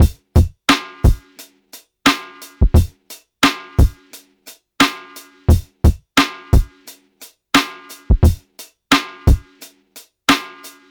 DRUM LOOPS
Revolution (175 BPM – Ebm)